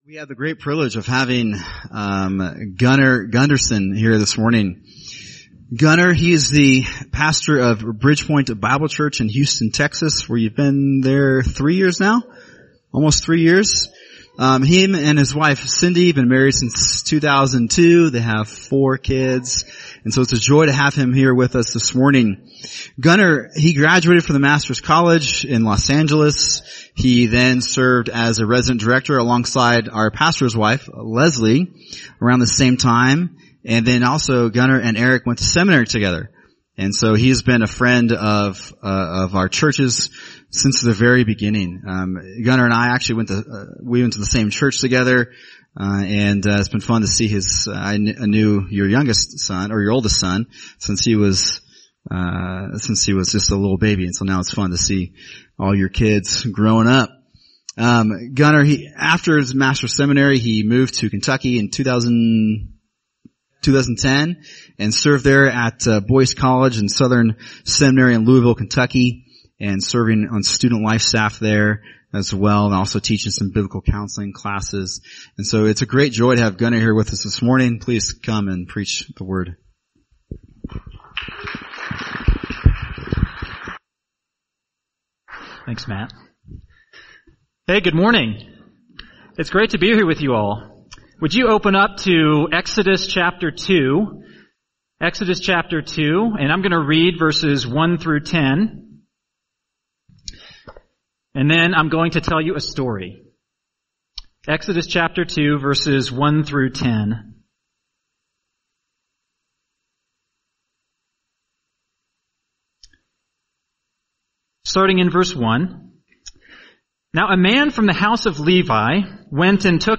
[sermon] Wrapped Up in Providence | Cornerstone Church - Jackson Hole